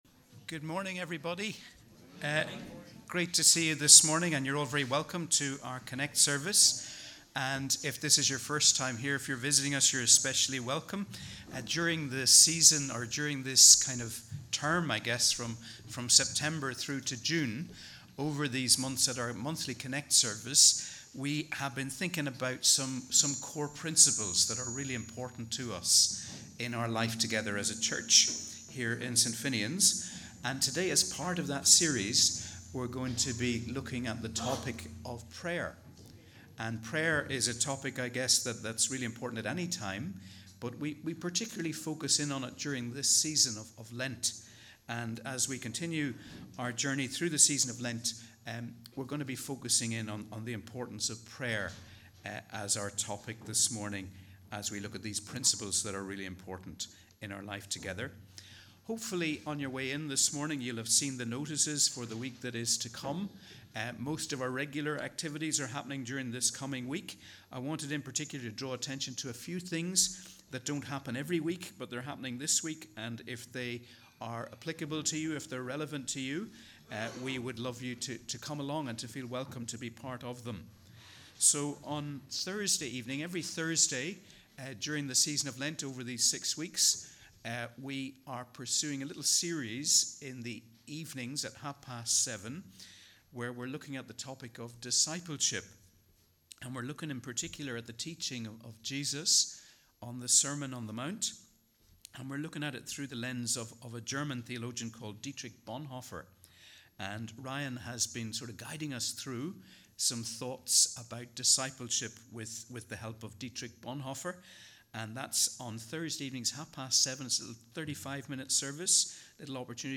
We warmly welcome you to our CONNEC+ service as we worship together on the 2nd Sunday in Lent.